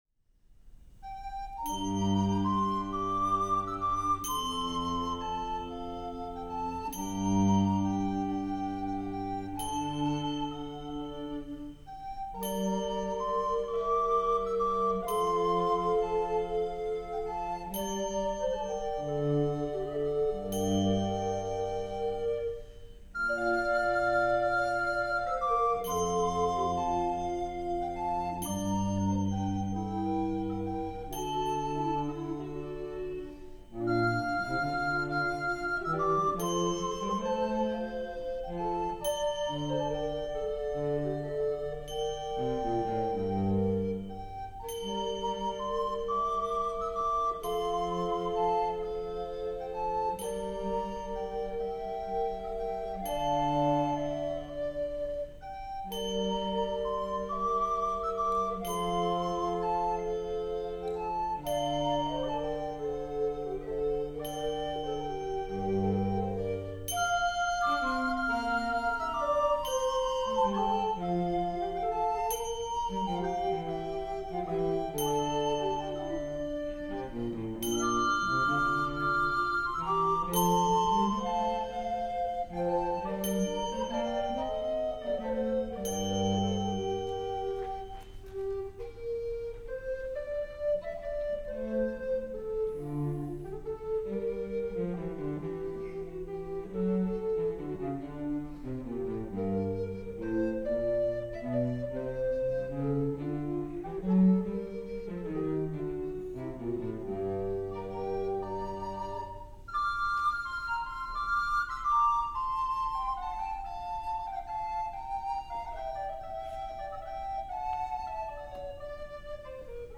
Audio examples from a recent recital:
This melody is scored here for recorders, cello, and percussion by Bergmann.